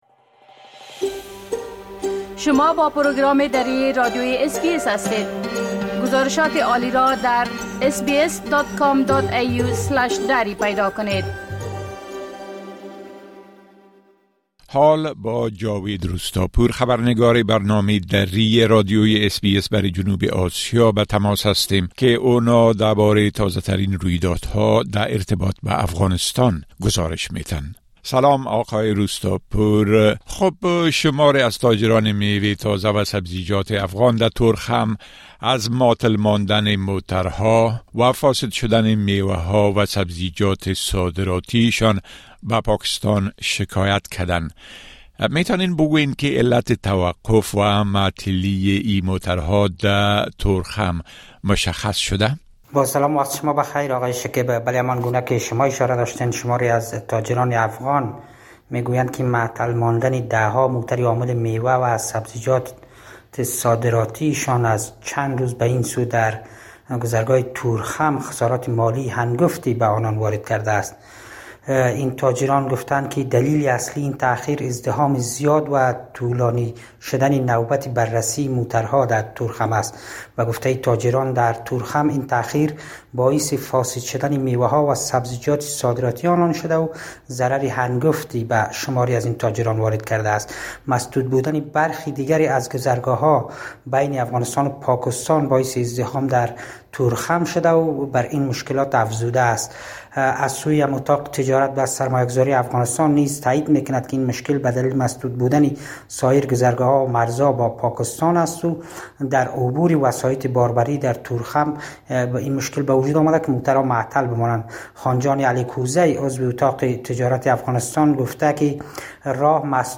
خبرنگار ما برای جنوب آسیا: تاجران افغان نگران اند كه ميوه ها و سبزيجات صادراتى شان بخاطر بسته شدن بندر تورخم فاسد خواهد شد
گزارش كامل خبرنگار ما، به شمول اوضاع امنيتى و تحولات مهم ديگر در افغانستان را در اين‌جا شنيده مى توانيد.